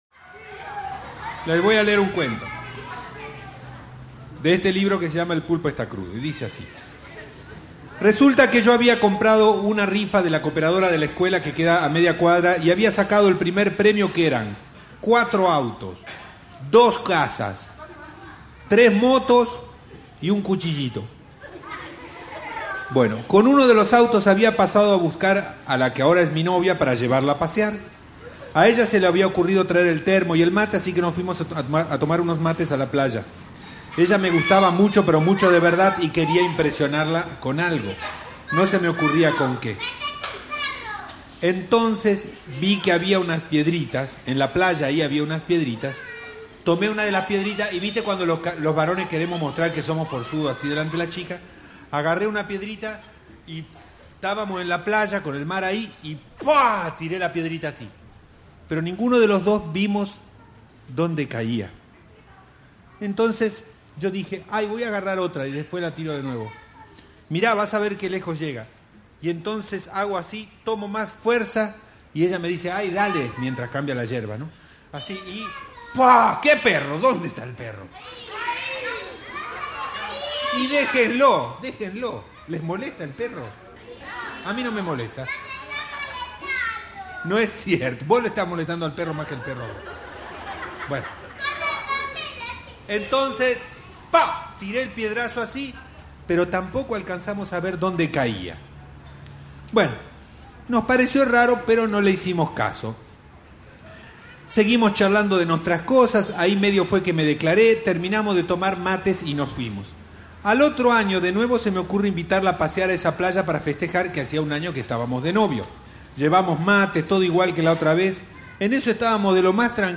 Grabación del cuento “El piedrazo” publicado en el libro El pulpo está crudo.